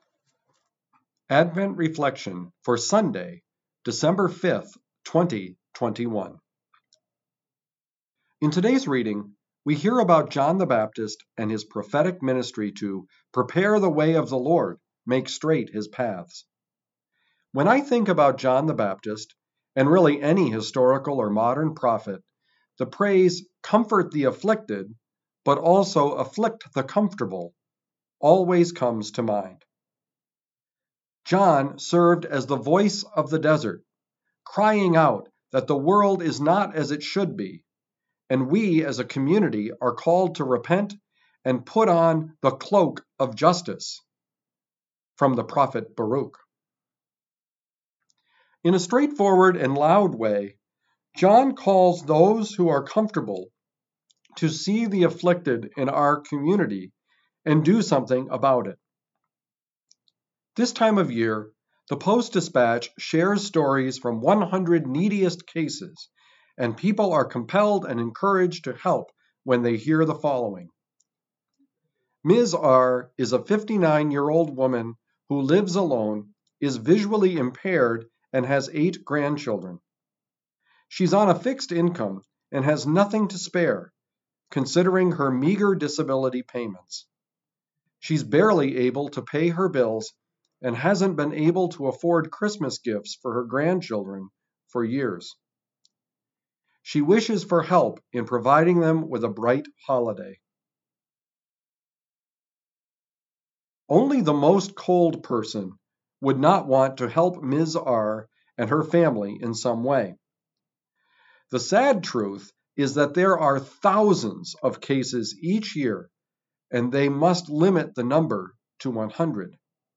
Advent Reflections